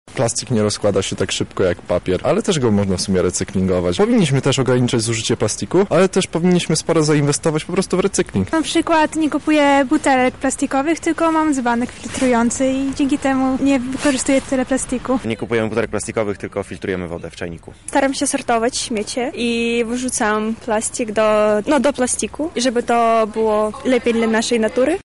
SONDA: Jak Lublinianie rezygnują z tworzyw sztucznych?
Zapytaliśmy mieszkańców Lublina o to, jak na co dzień rezygnują z tworzyw sztucznych:
Sonda